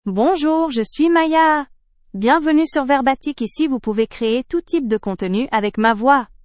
FemaleFrench (Canada)
MayaFemale French AI voice
Maya is a female AI voice for French (Canada).
Voice sample
Maya delivers clear pronunciation with authentic Canada French intonation, making your content sound professionally produced.